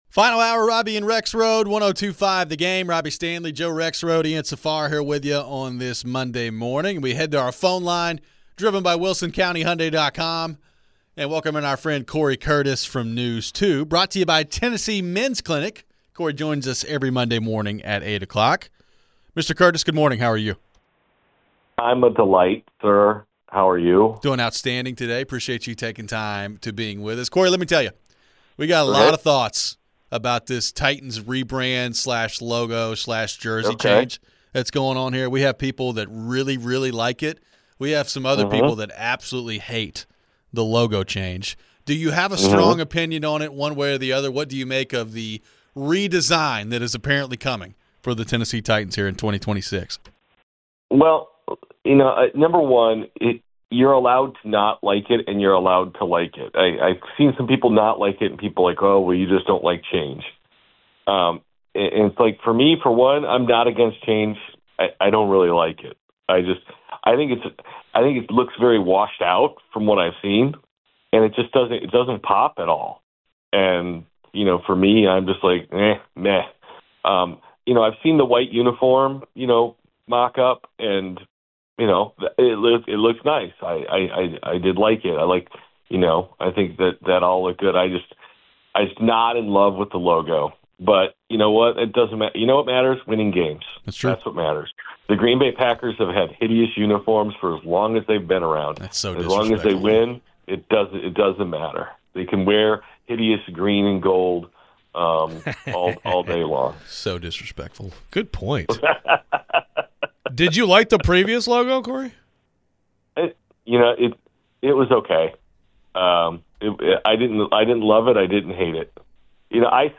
We wrap up the show with your final phones.